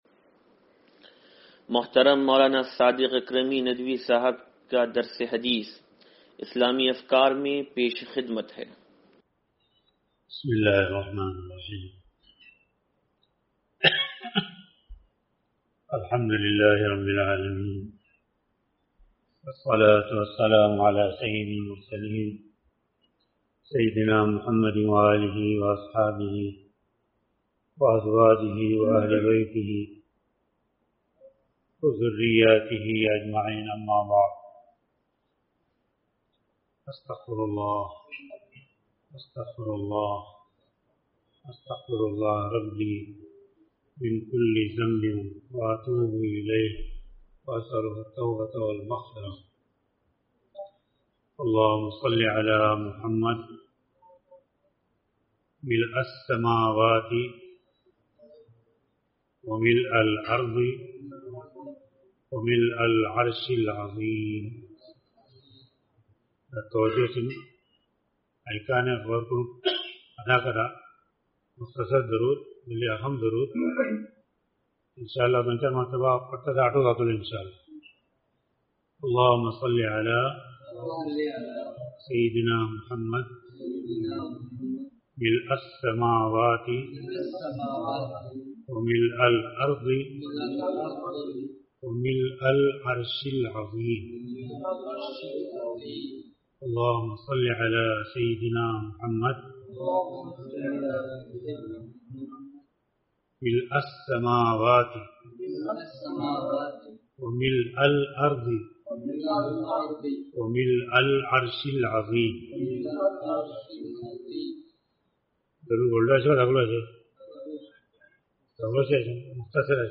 درس حدیث نمبر 0594